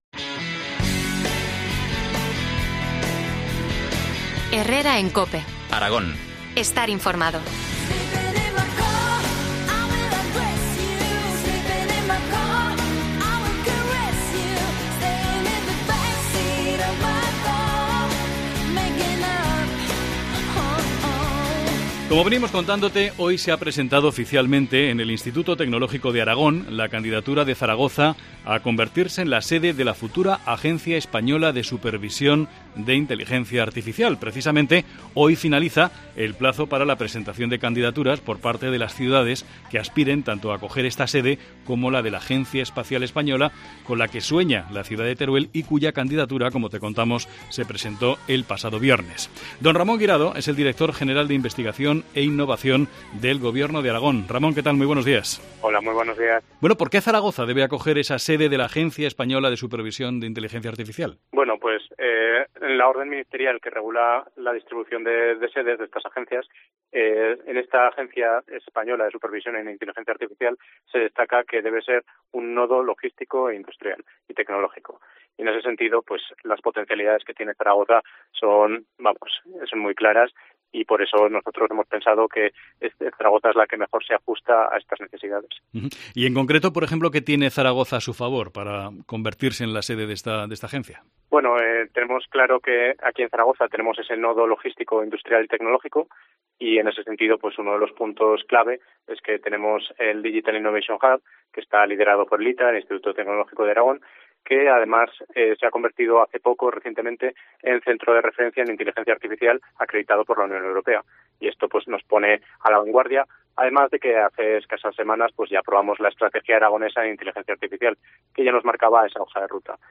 Entrevista a Ramón Guirado, director general de Investigación e Innovación del Gobierno de Aragón.